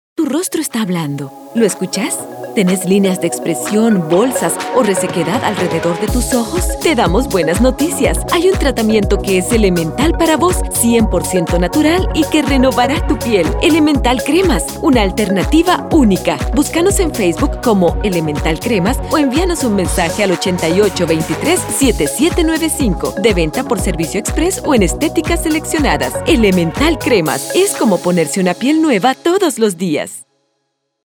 More than 25 years of experience recording commercials, e-learning, radio news, theatre, documentals, etc. in spanish latin american neutral.
I MAC with Pro Tools AKG 4000 microphone Pre amp Tube Bellari, DBX Compressor, Module Aphex
Sprechprobe: Werbung (Muttersprache):